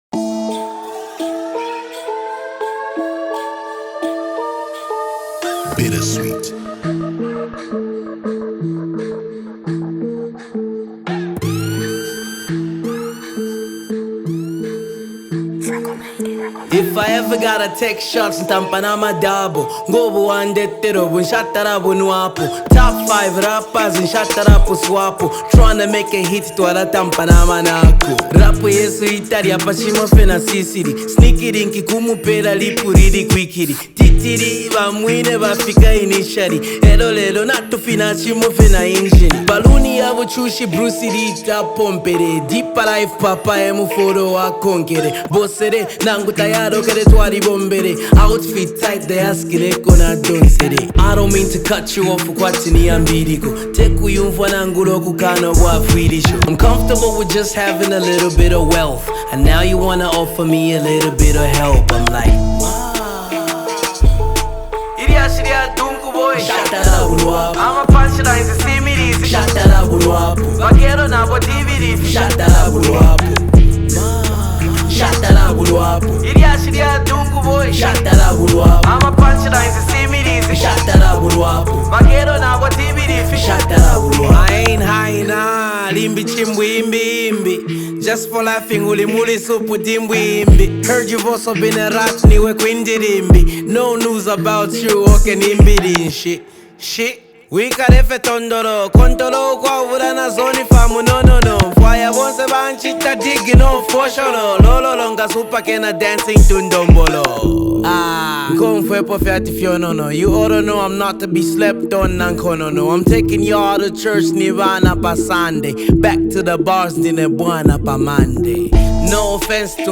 Zambian rapper and lyricist